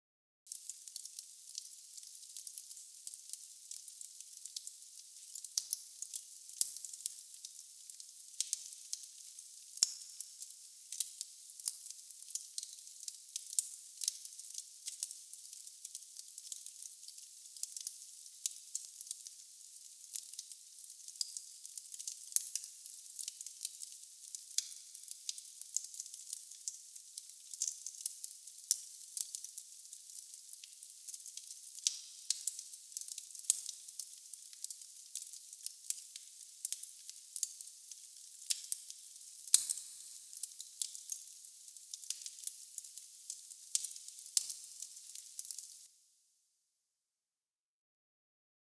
FireplaceSoundMain.wav